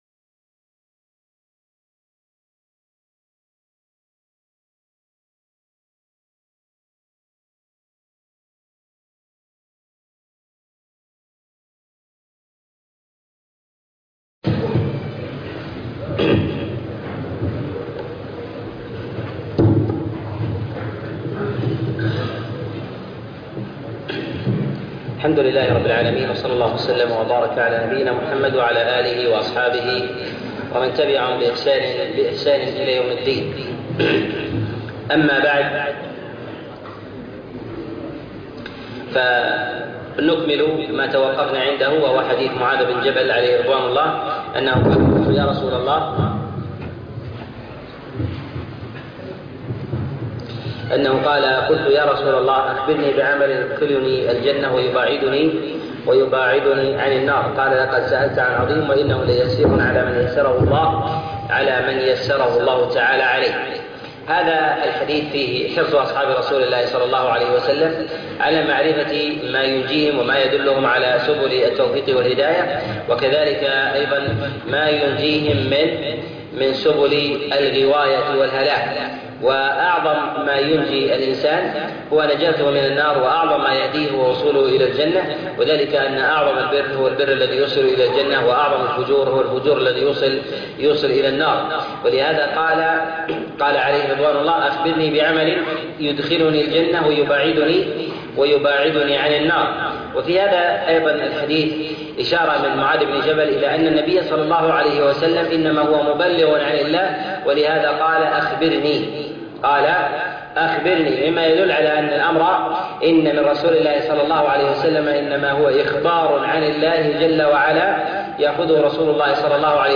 درس 4 - شرح الأربعين النووية